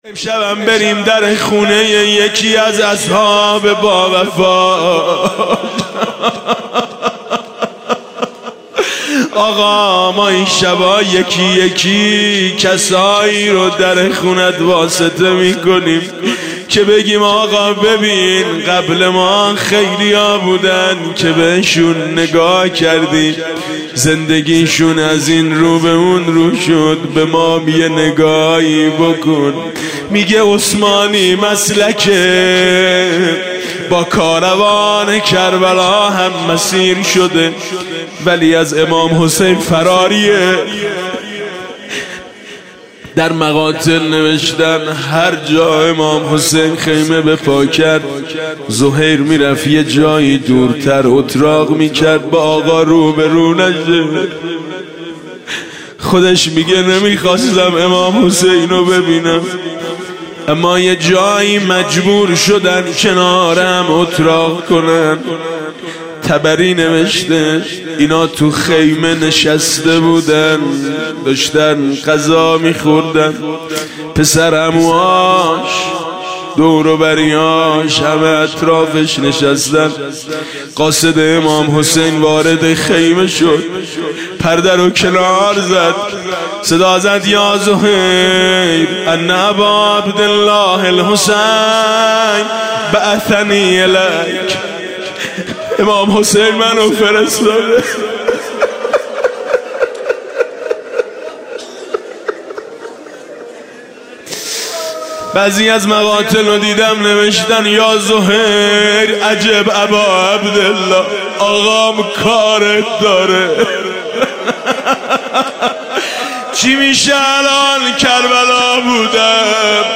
دانلود مناجات خوانی حاج میثم مطیعی – شب ششم ماه مبارک رمضان۹۷
امام پیک فرستاده در پی‌ات، برخیز! (روضه حضرت زهیر “علیه السلام”)
من و شرمندگی از جرم و خطا خدای من (مناجات با خدا)